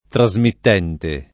[ tra @ mitt $ nte ]